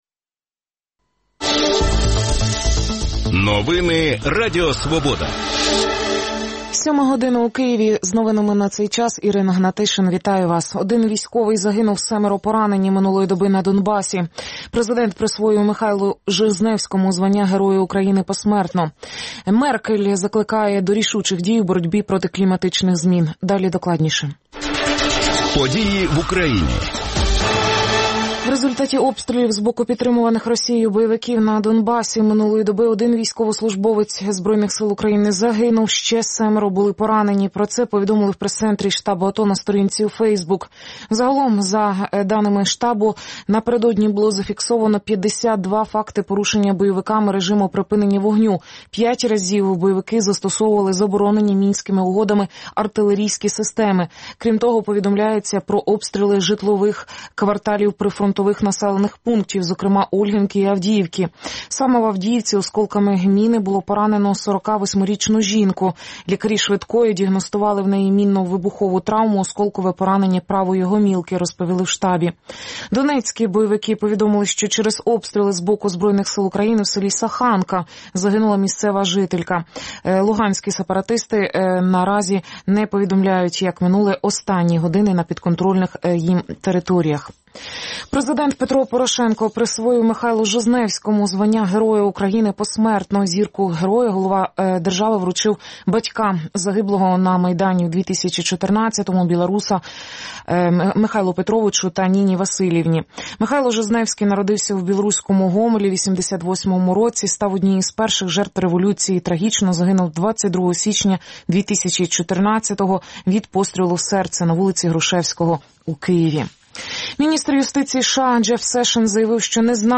говоритиме з гостями студії